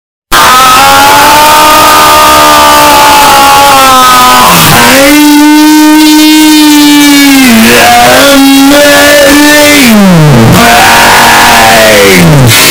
• Качество: 313, Stereo
громкие
из игр
крик